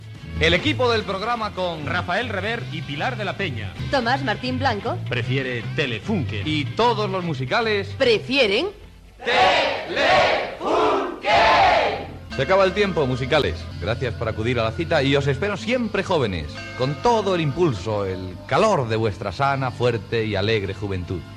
Equip, publicitat i comiat del programa
Musical